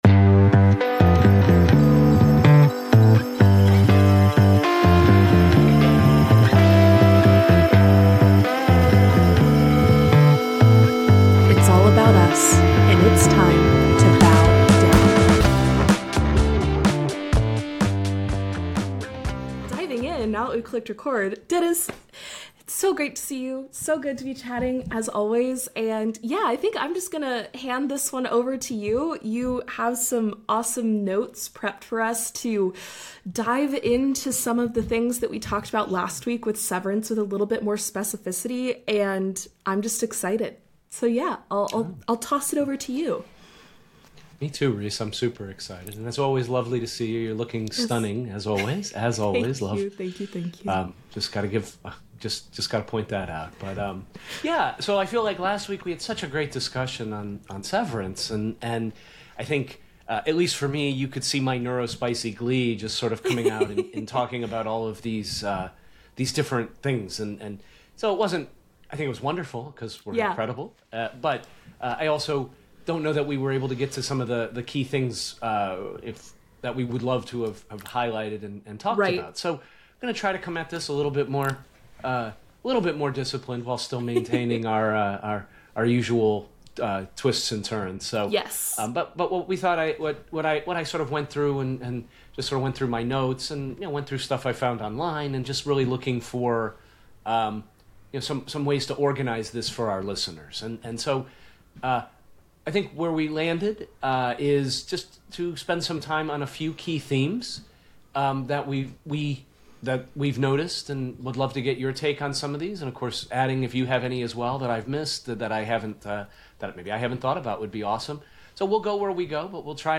conversations